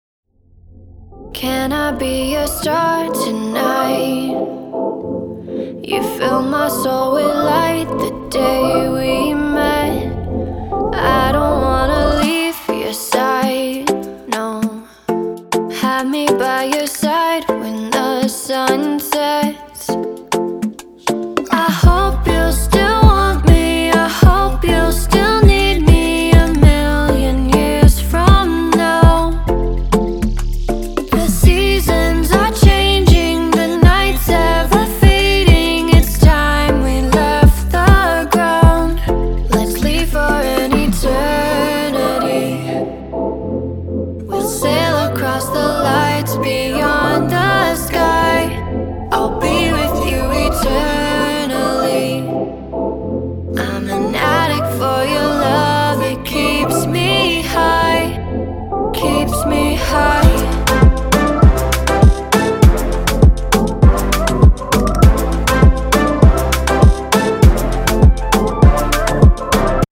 包含5条完整的无伴奏曲音轨，由两位专业歌手录制和编辑，一男一女。
除了这些高质量的无伴奏合唱之外，我们还包括75个旋律ad-lib和20个很棒的人声回路。